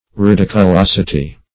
Search Result for " ridiculosity" : The Collaborative International Dictionary of English v.0.48: Ridiculosity \Ri*dic`u*los"i*ty\, n. The quality or state of being ridiculous; ridiculousness; also, something ridiculous.